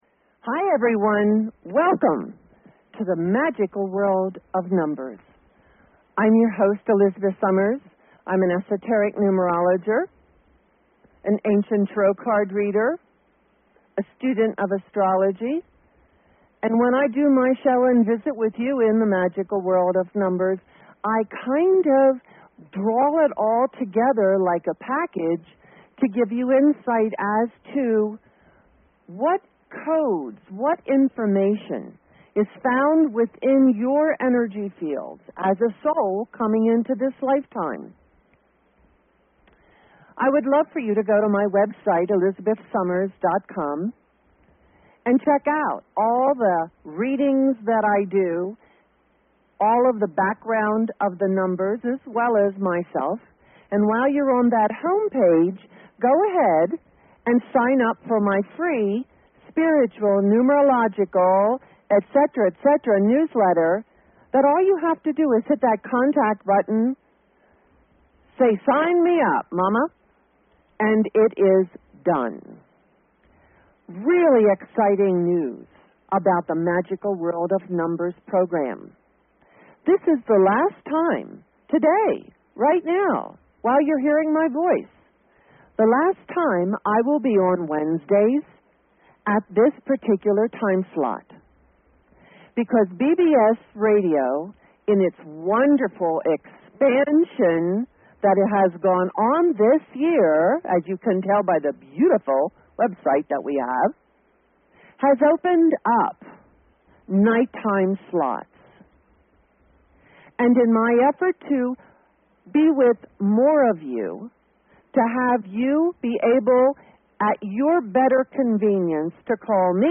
Talk Show Episode, Audio Podcast, Magical_World_of_Numbers and Courtesy of BBS Radio on , show guests , about , categorized as